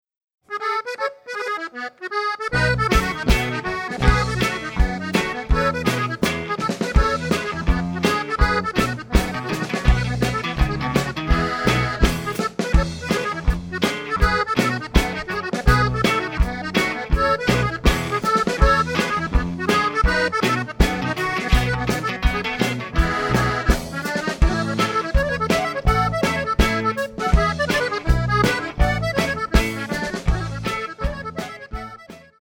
banjo sexto, vocals
bass guitar, vocals
drums